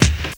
Combo Rnb.wav